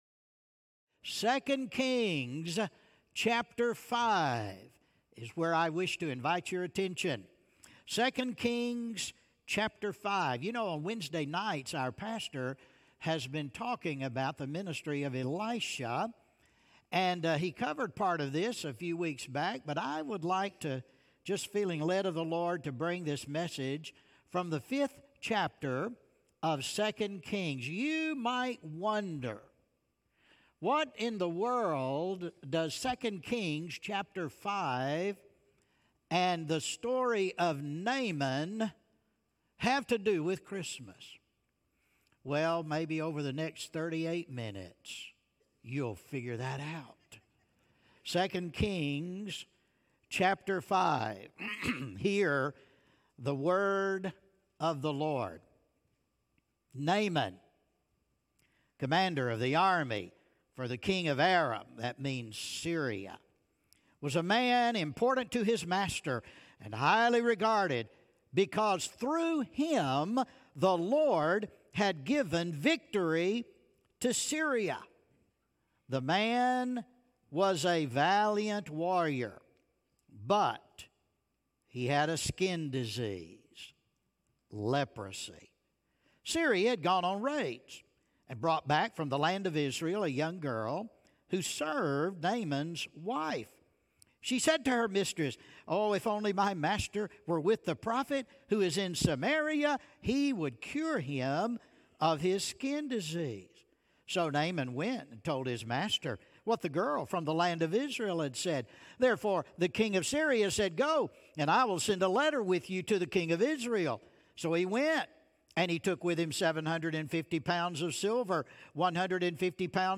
Sermon Listen Worship The story of Naaman, a powerful Syrian commander, reveals how God uses unlikely people for His purposes.